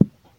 beeb kick 7
Tags: 808 drum cat kick kicks hip-hop